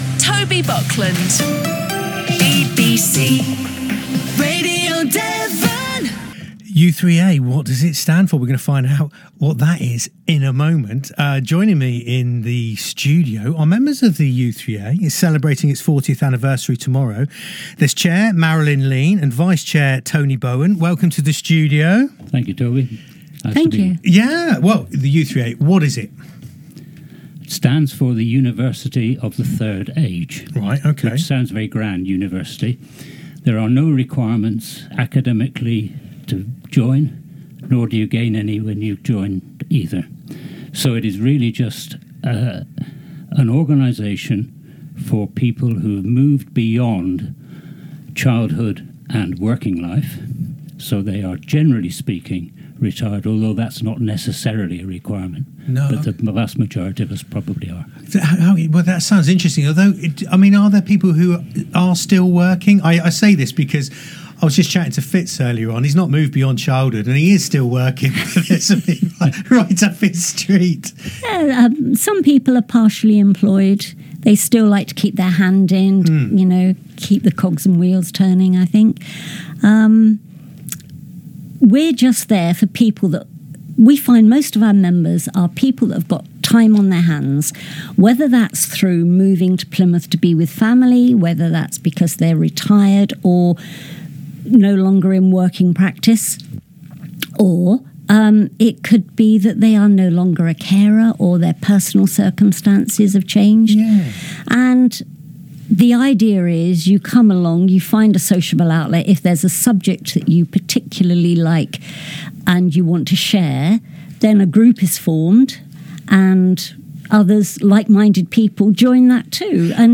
Plymouth u3a Interview on BBC Radio Devon